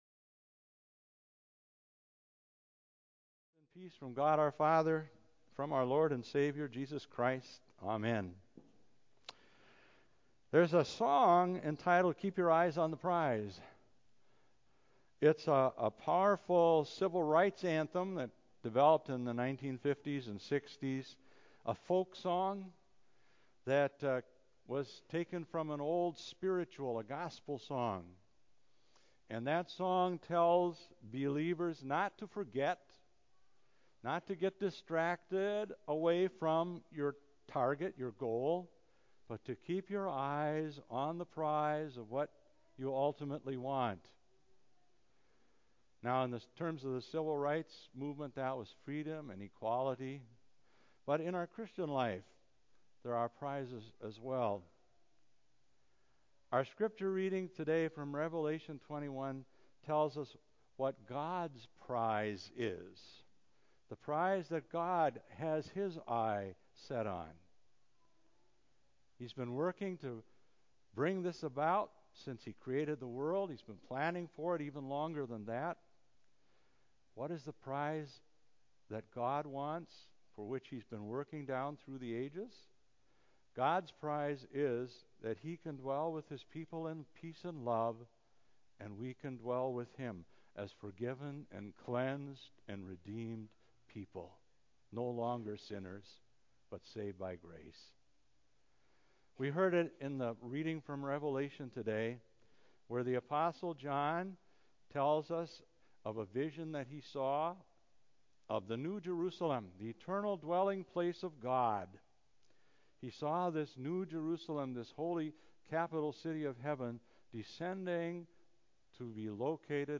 church-sermon5.19-CD.mp3